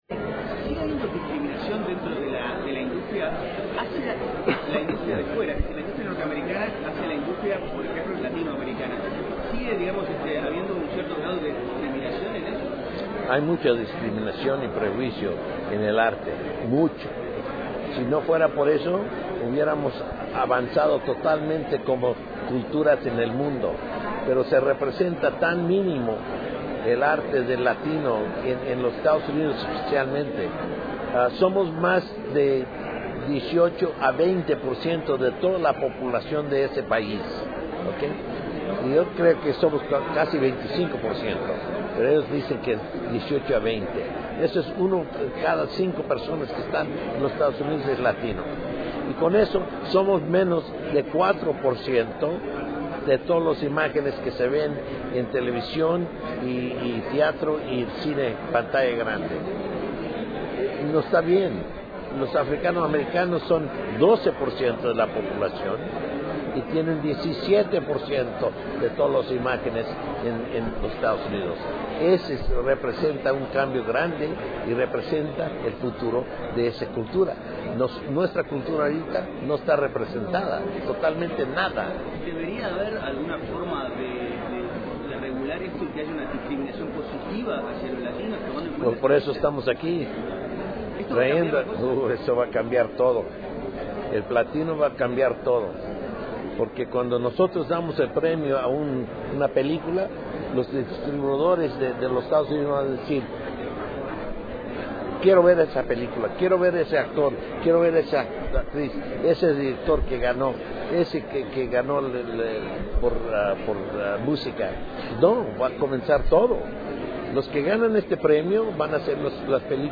Edward James Olmos en conferencia de prensa